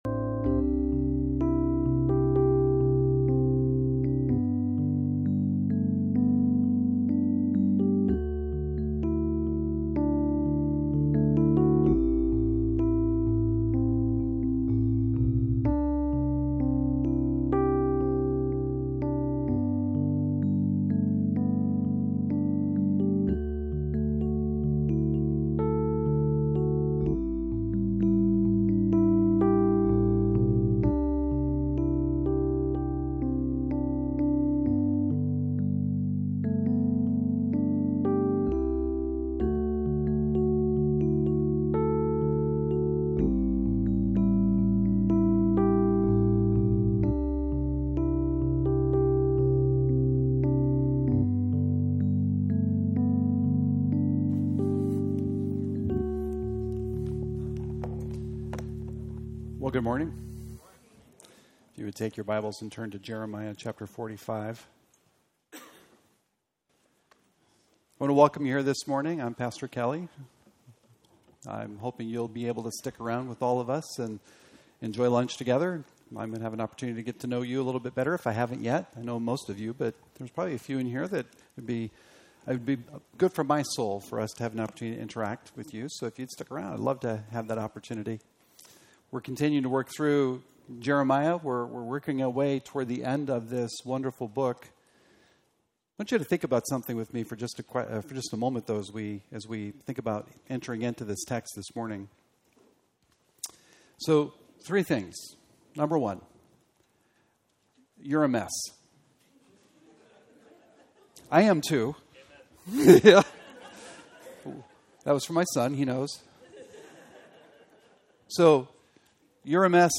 sermon3-9-25.mp3